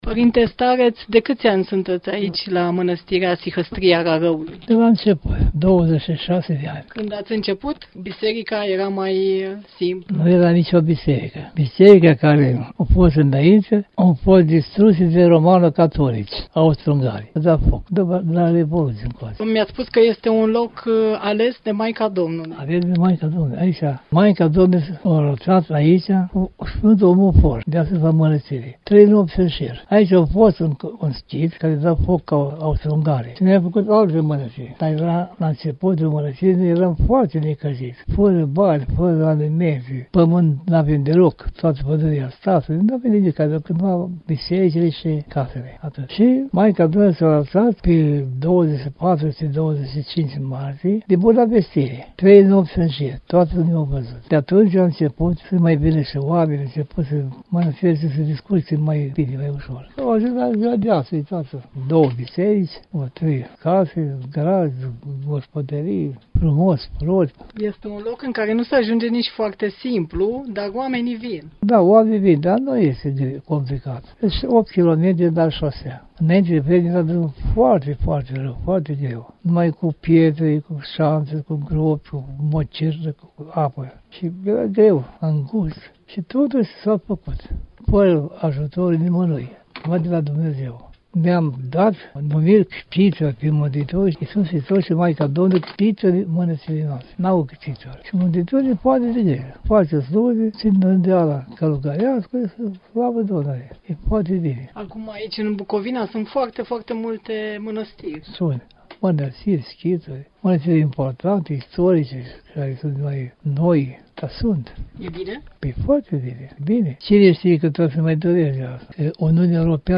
În căutarea liniștii interioare. Vizită la Mănăstirea Sihăstria Rarăului.